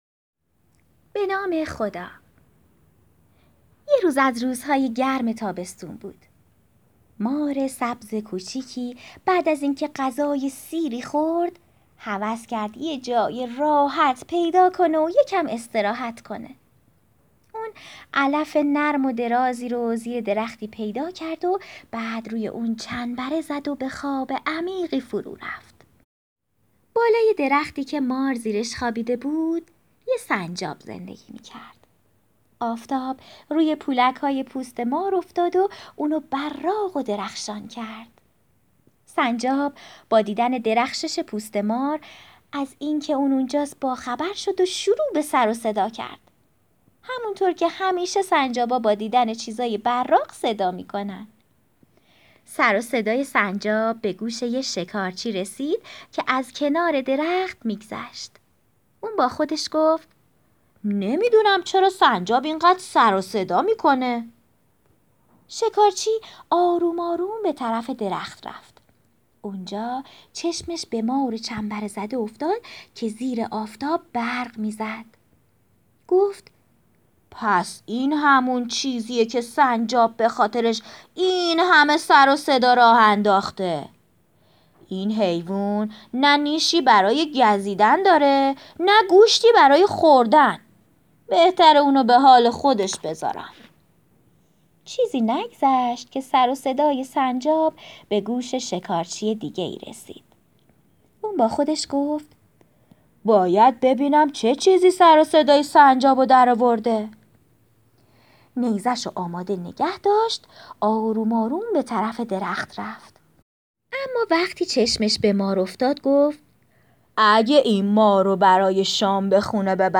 قصه گویی